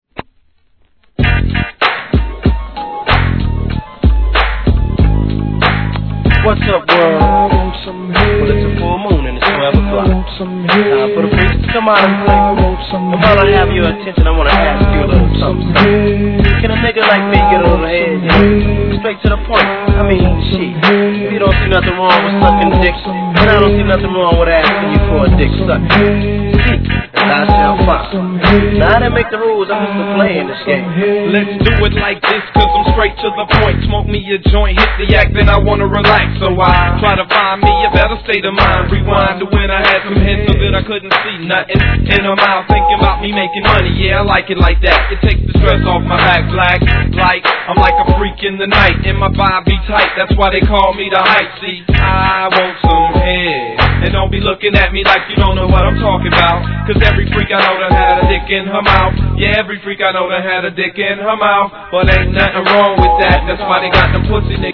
G-RAP/WEST COAST/SOUTH
バキバキ・スネアがG-FUNKファン怒ツボでしょう!!